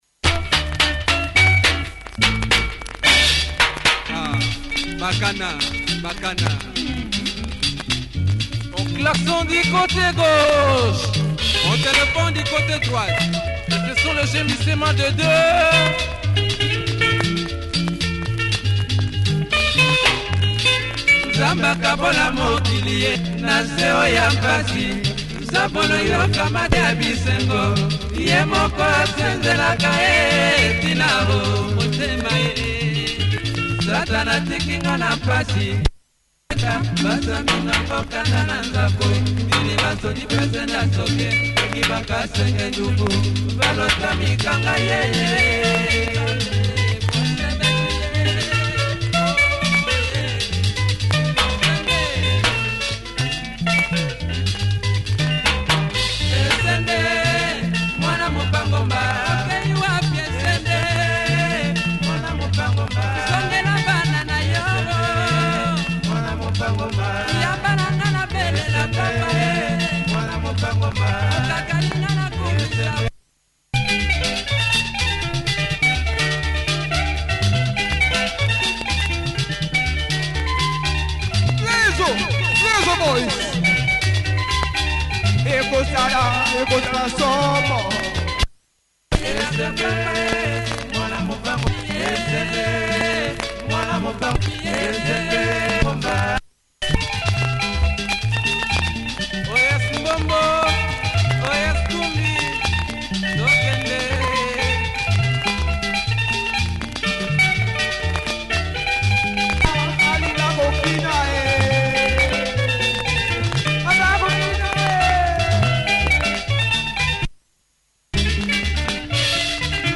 Classic Lingala